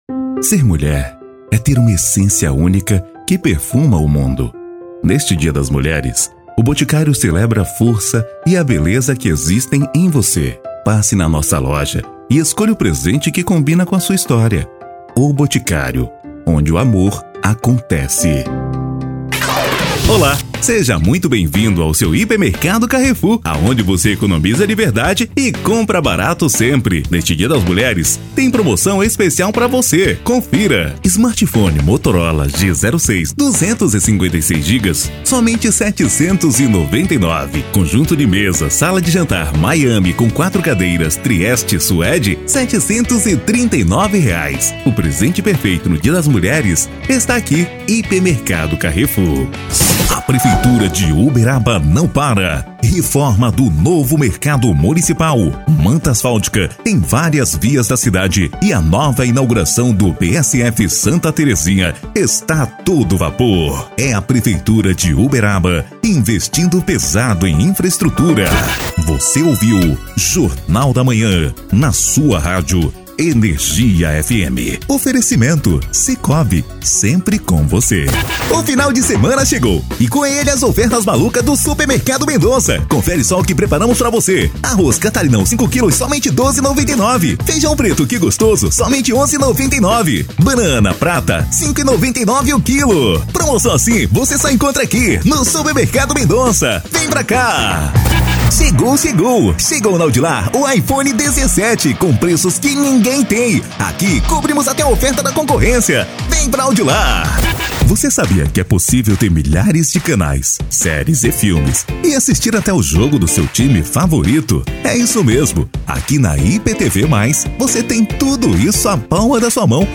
Spot Comercial
Vinhetas
Impacto
Animada